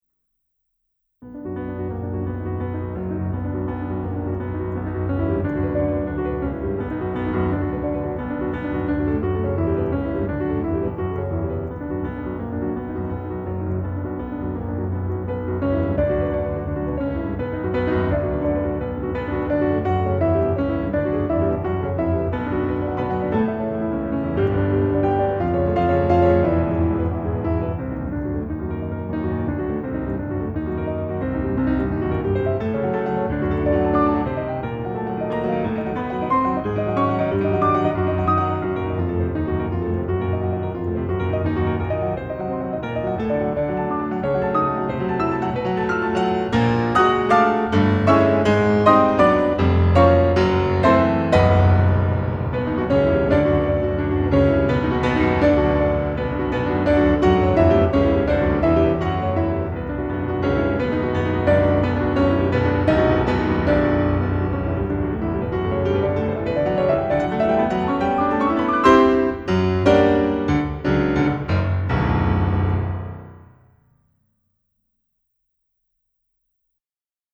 Prélude No. 6 in B Minor, Op. 38: Allegro molto